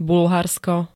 Zvukové nahrávky niektorých slov
2k3s-bulharsko.ogg